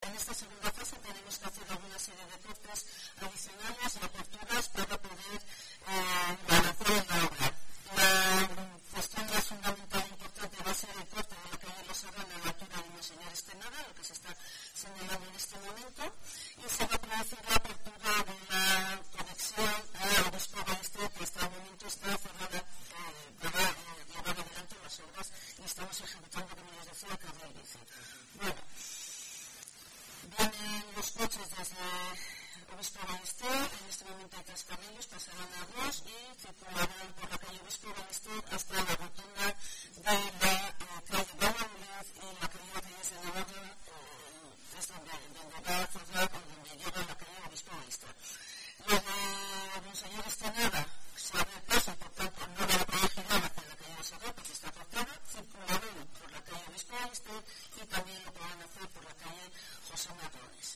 Beatriz Artolazabal,teniente de alcaldesa de Vitoria sobre las afecciones de las obras de Los Herrán